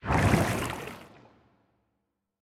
Minecraft Version Minecraft Version snapshot Latest Release | Latest Snapshot snapshot / assets / minecraft / sounds / block / bubble_column / upwards_inside.ogg Compare With Compare With Latest Release | Latest Snapshot